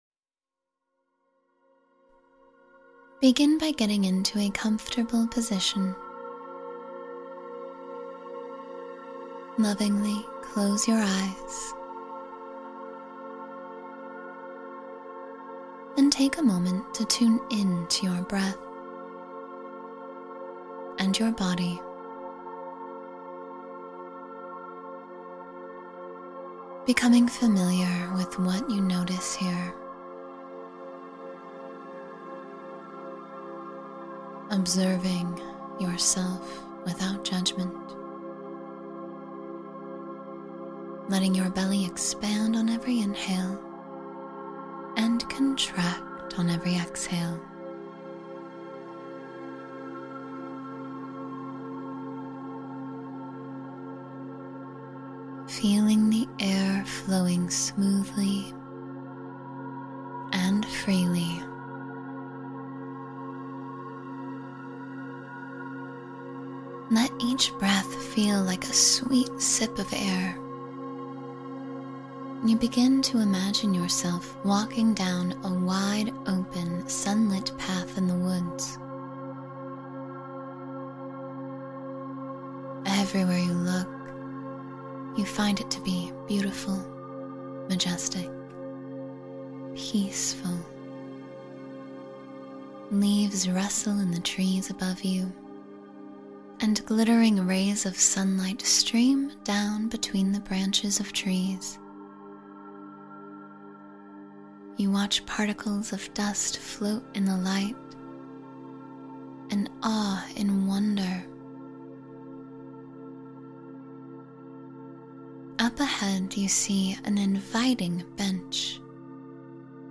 Feel-Good Visualization for a Brighter Day — Guided Meditation for Joy